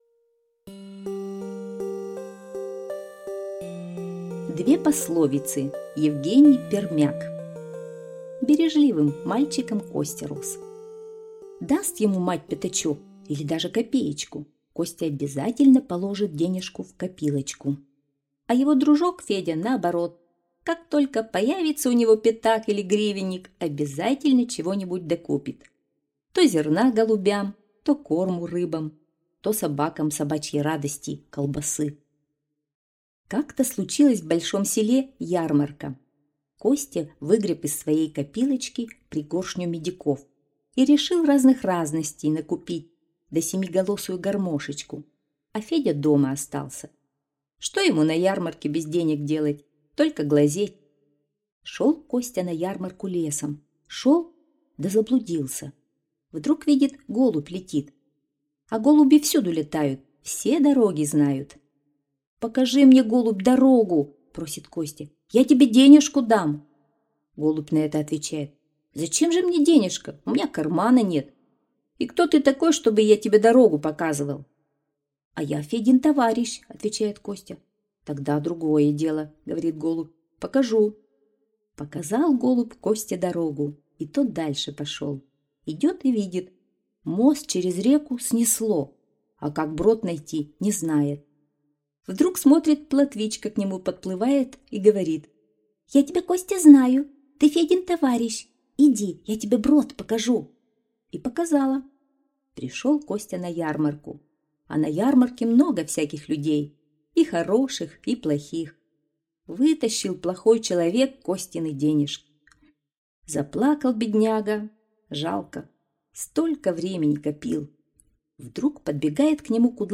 Аудиосказка «Две пословицы»